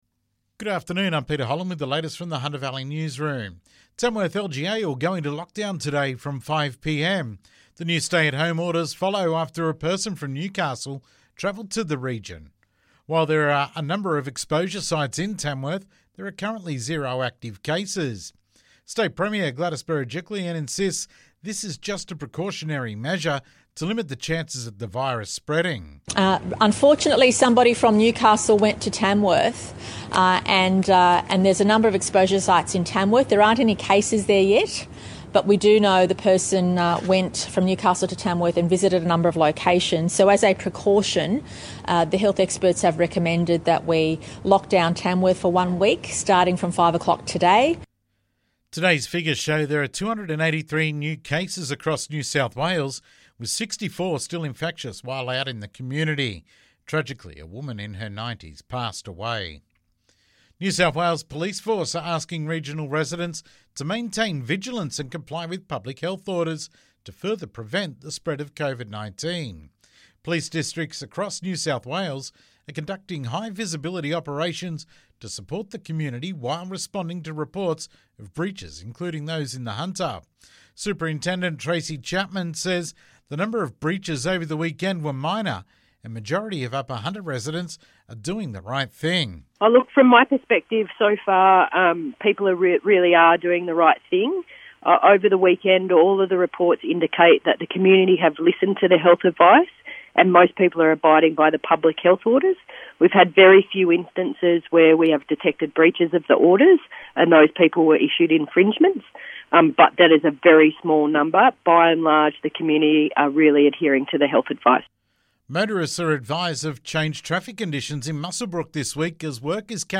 Listen: Hunter Local News Headlines 09/08/2021